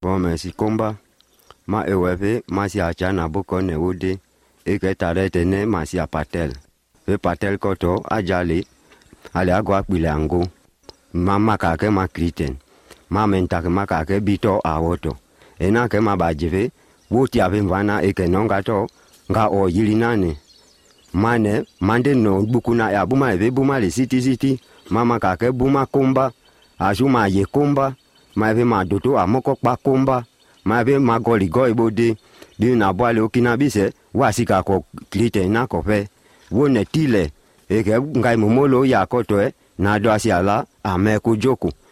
Two or three level tones. Lots of prenasalised voiced stops: [m͡b], [n͡d], [ŋ͡g].
Clearly identifiable difference between [e], [o], [u] and open [ɛ], [ɔ], [ʊ]. Laminal/near-retroflex pronunciation of the coronals.